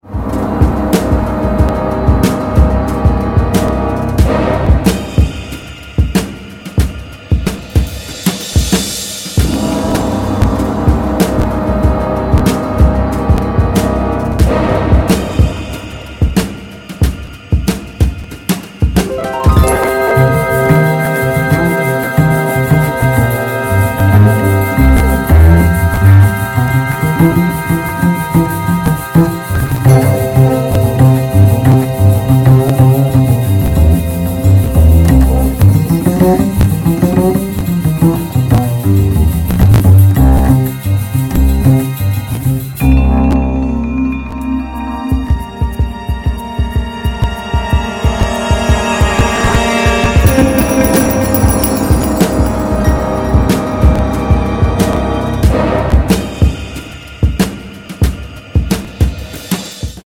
Another stellar film score by the Maestro